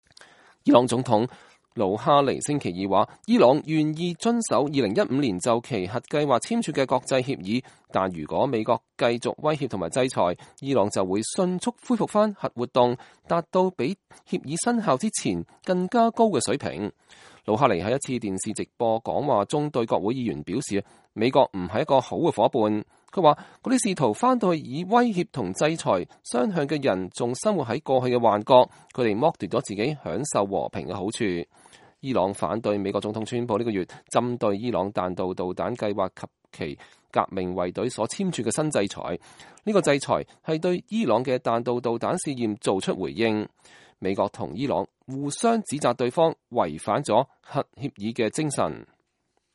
魯哈尼在一次電視直播的講話中對國會議員表示，美國不是一個“好夥伴”。